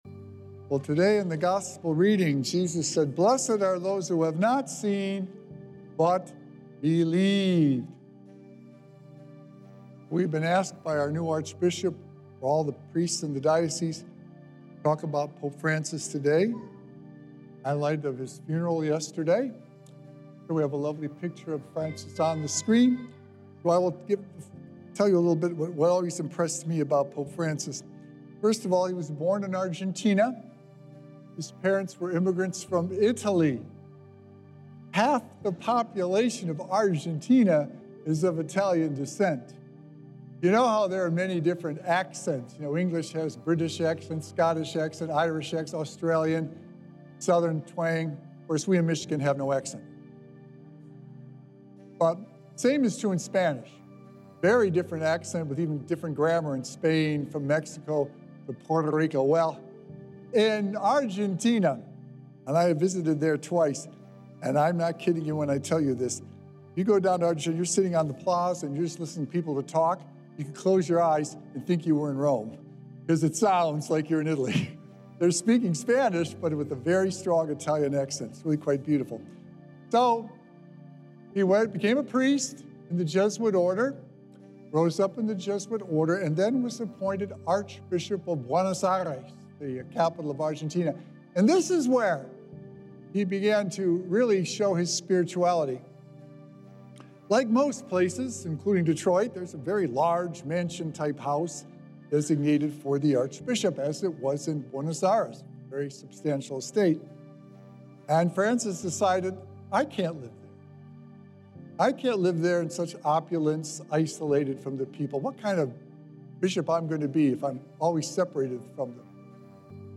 His selfless way of living is a beautiful reminder to bring Jesus to others. Recorded Live on Sunday, April 27th, 2025 at St. Malachy Catholic Church